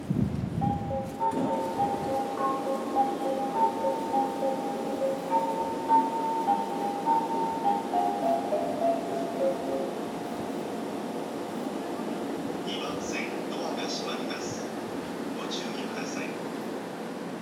新白岡駅　Shin-Shiraoka Station ◆スピーカー：ユニペックス小丸型
2番線発車メロディー